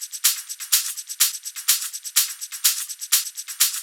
Live Percussion A 16.wav